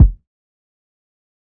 TC Kick 13.wav